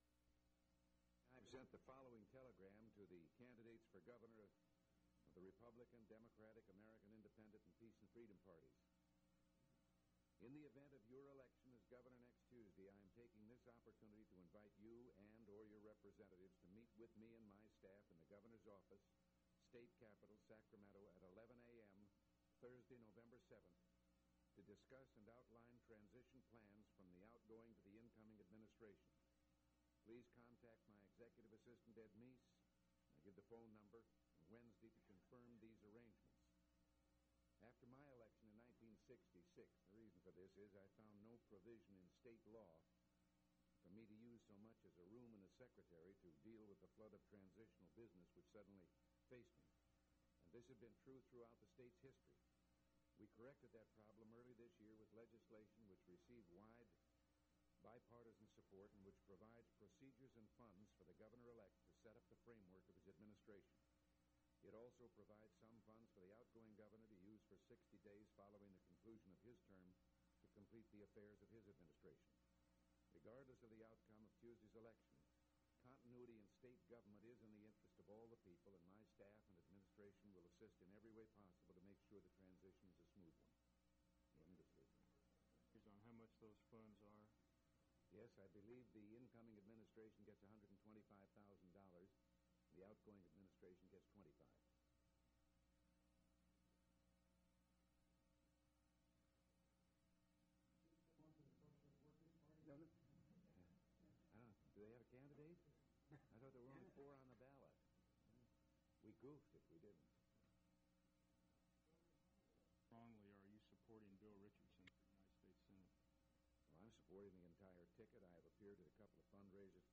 Governor Ronald Reagan News Conference
Audio Cassette Format.